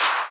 b_clap_v127l4o5c.ogg